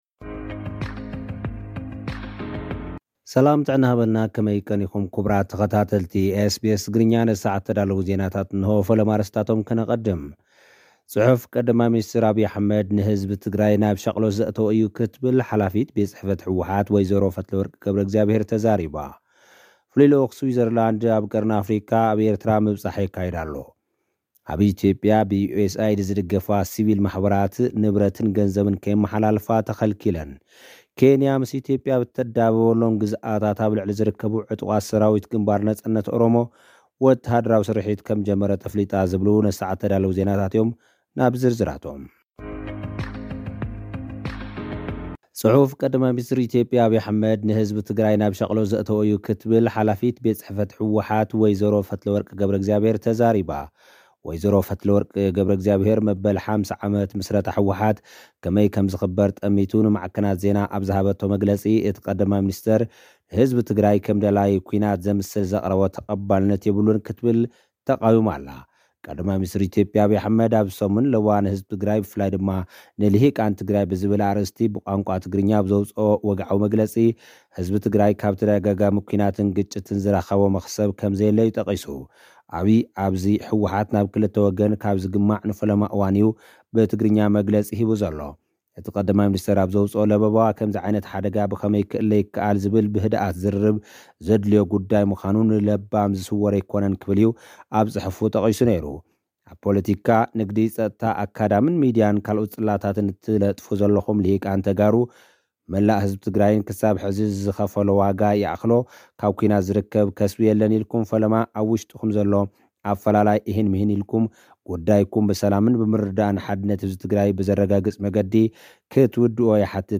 ልኡኽና ዝሰደደልና ጸብጻብ ኣርእስታት፡ ፍሉይ ልኡኽ ስዊዘርላንድ ኣብ ቀርኒ ኣፍሪቃ ኣብ ኤርትራ ምብጻሕ ኣካይዱ። ኣብ ኢትዮጵያ፡ ብዩኤስኤይድ ዝድገፋ ሲቪል ማሕበራት ንብረትን ገንዘብን ከየመሓላልፋ ተኸልኪለን። ኬንያ ኣብ ልዕሊ ዕጡቓት ሰራዊት ኦነግ "ወተሃደራዊ ስርሒት" ጀሚራ.።